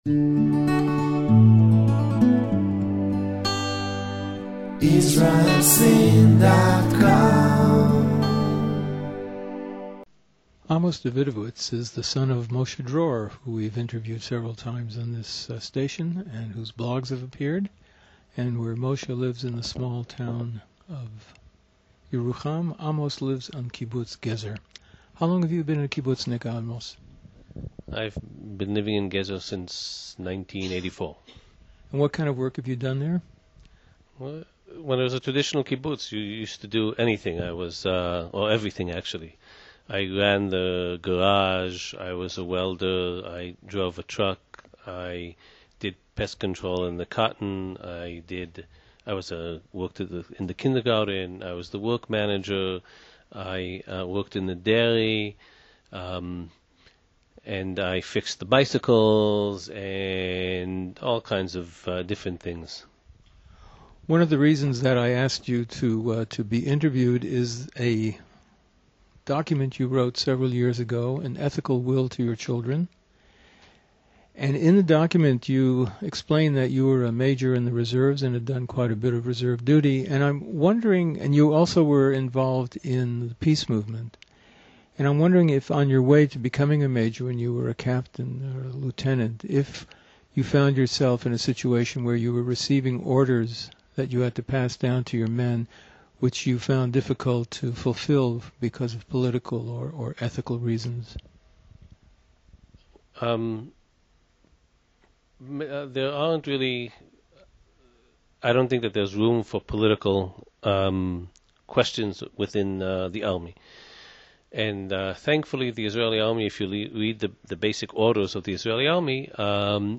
A fascinating interview that provides us with an unique view of the current situation in Israel.